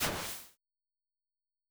snd_splash.wav